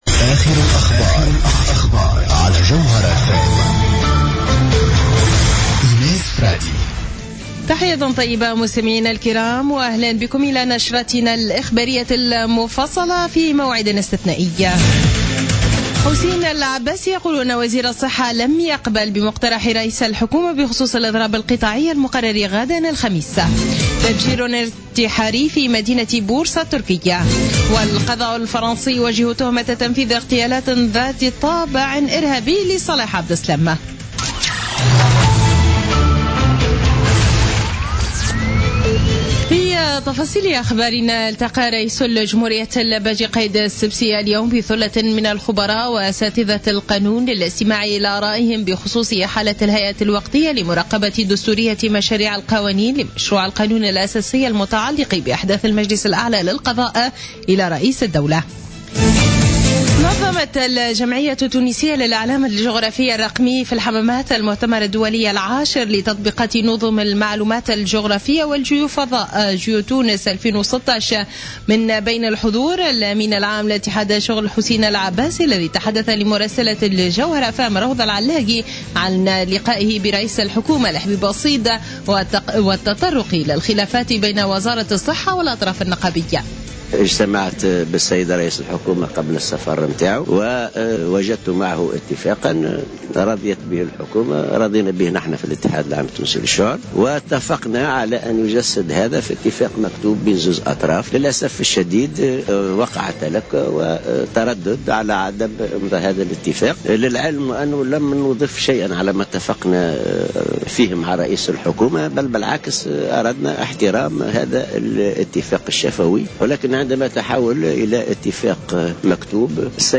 نشرة أخبار السابعة مساء ليوم الأربعاء 27 أفريل 2016